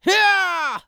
CK长声04.wav
CK长声04.wav 0:00.00 0:00.89 CK长声04.wav WAV · 77 KB · 單聲道 (1ch) 下载文件 本站所有音效均采用 CC0 授权 ，可免费用于商业与个人项目，无需署名。
人声采集素材/男2刺客型/CK长声04.wav